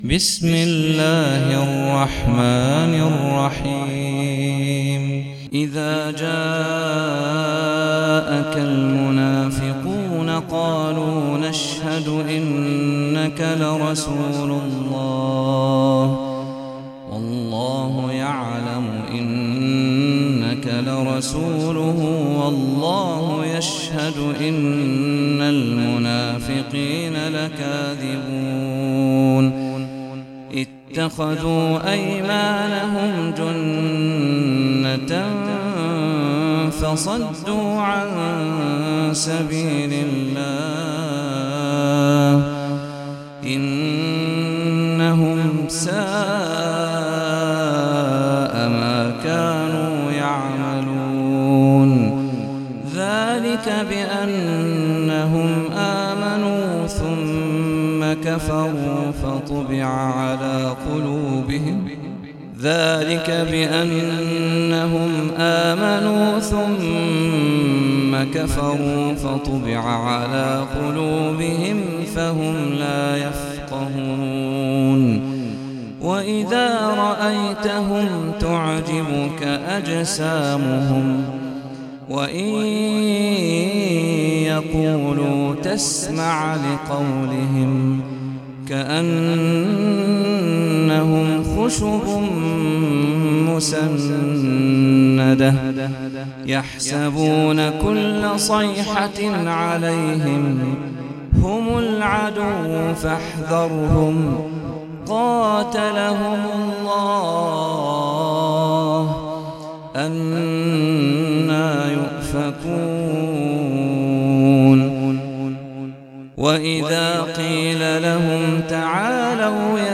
سورة المنافقون - صلاة التراويح 1446 هـ (برواية حفص عن عاصم)